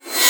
VEC3 FX Reverse 38.wav